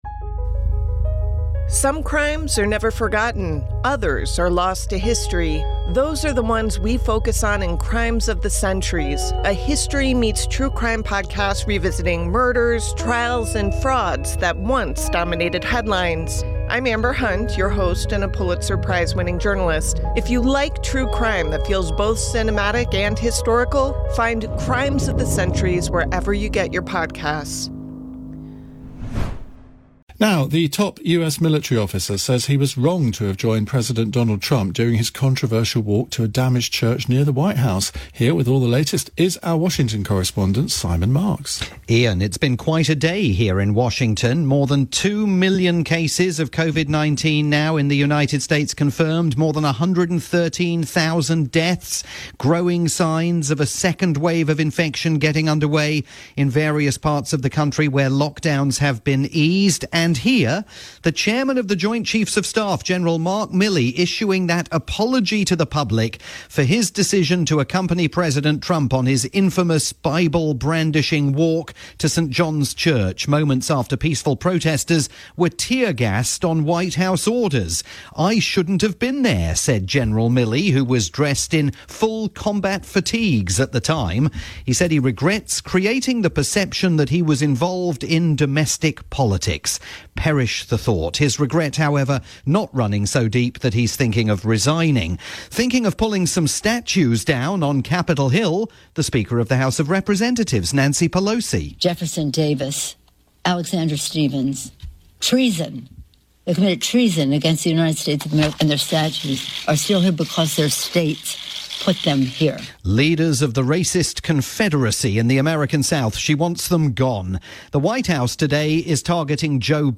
live report for Iain Dale's nightly programme on LBC.